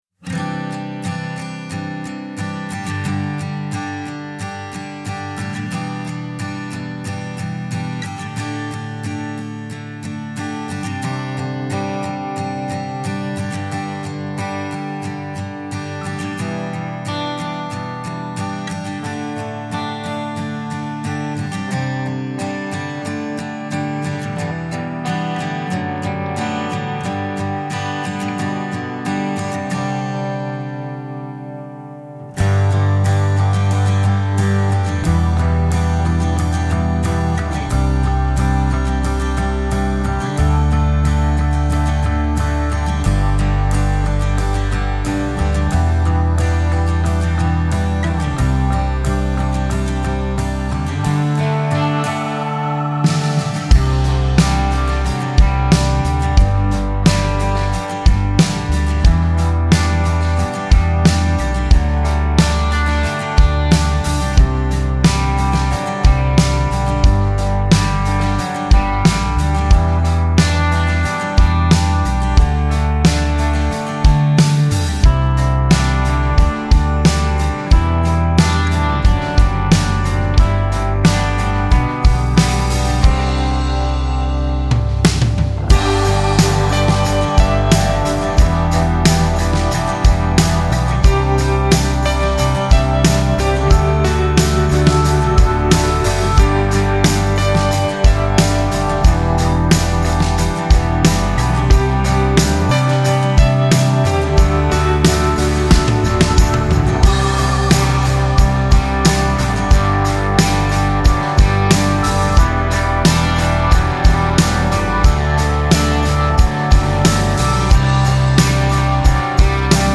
Key G
Instrumental Track